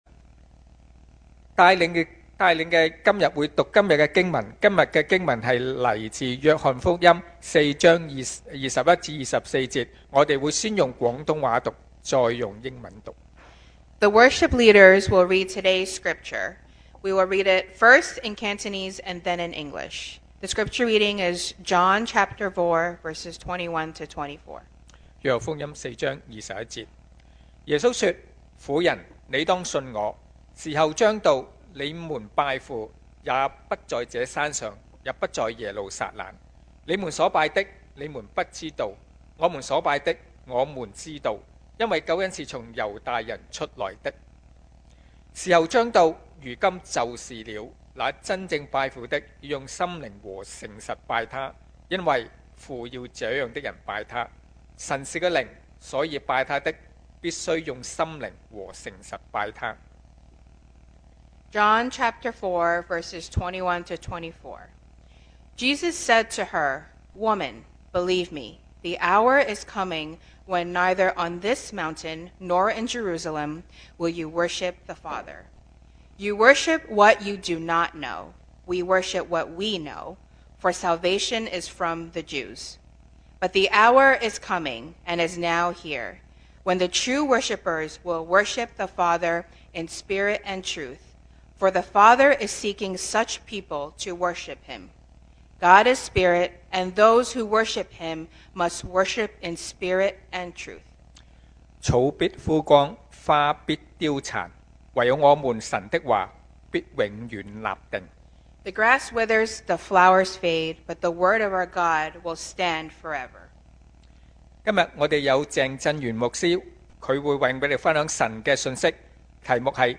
2026 sermon audios
Service Type: Sunday Morning